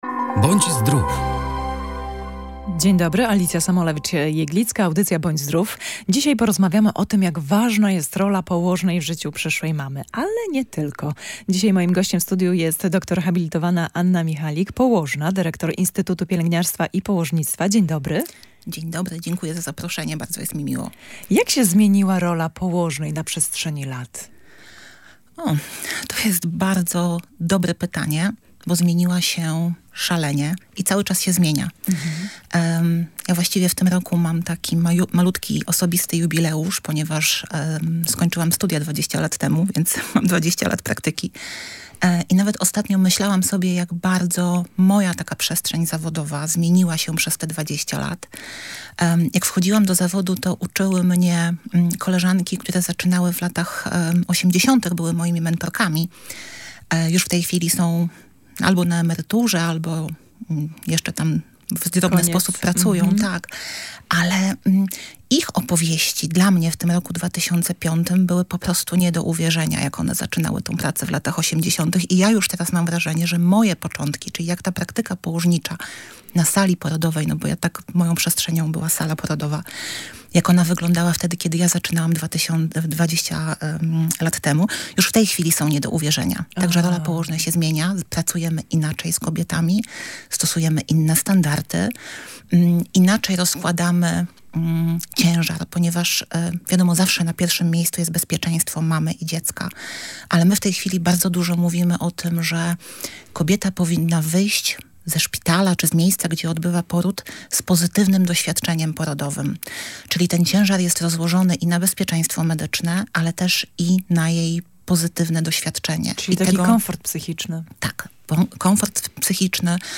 W audycji „Bądź Zdrów” rozmawialiśmy o tym, jak ważna jest położna w życiu przyszłej mamy.